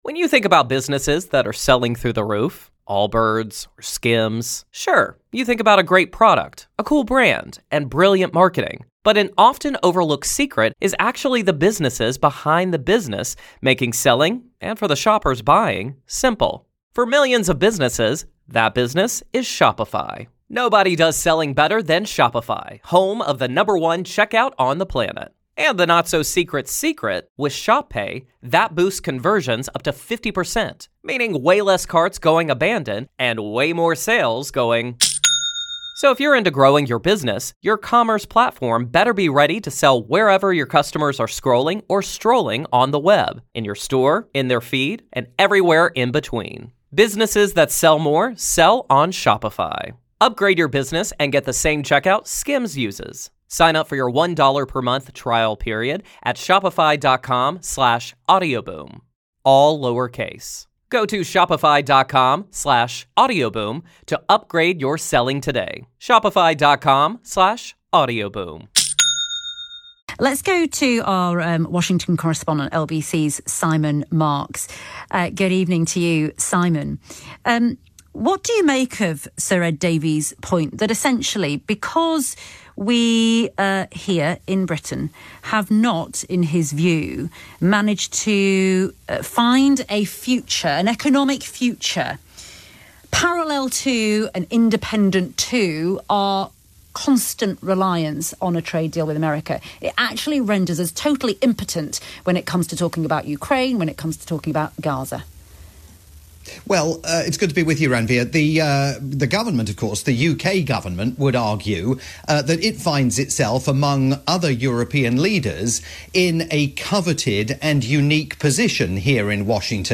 chat with LBC's Ranvir Singh